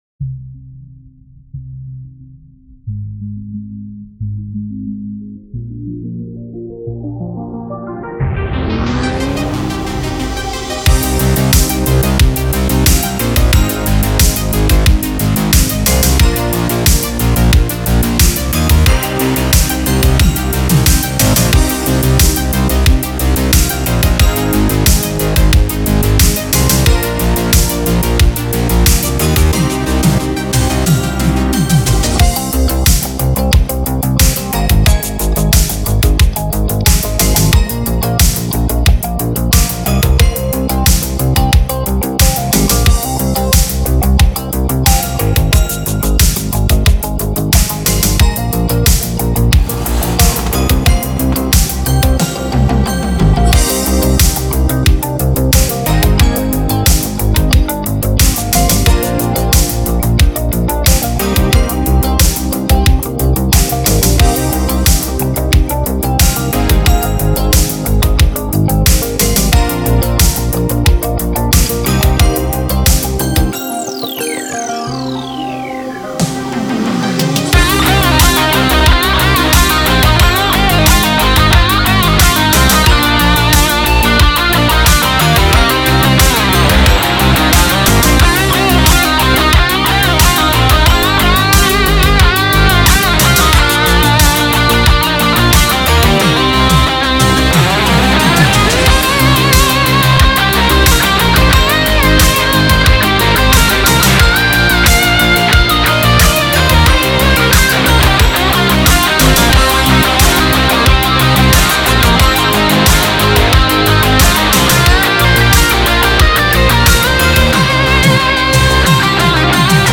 MX5 - Synthwave rock track
Hey guys - my new synthwave rock-fusion track. Appreciate any feedback on the mix and guitarwork.